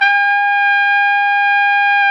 Index of /90_sSampleCDs/Roland L-CD702/VOL-2/BRS_Tpt 5-7 Solo/BRS_Tp 5 RCA Jaz